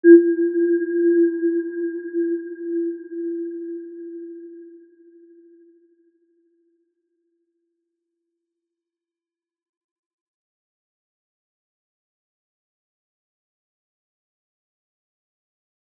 Aurora-G4-mf.wav